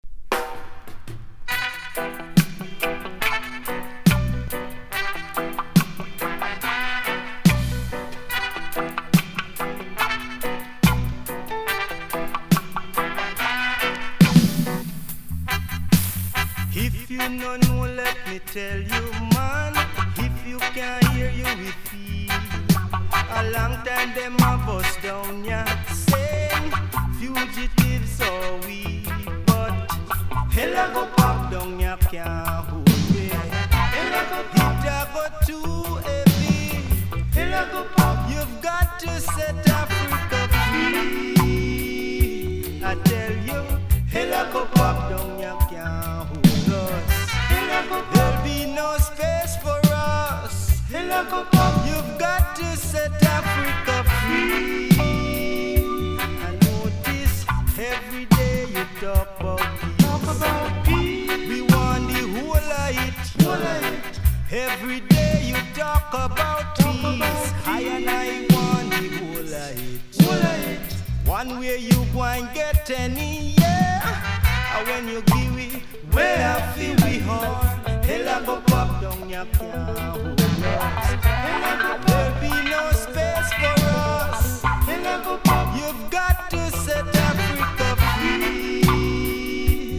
• REGGAE-SKA
ひたすら気持ち良いワンドロップのリディムに甘いハーモニーが響く1枚！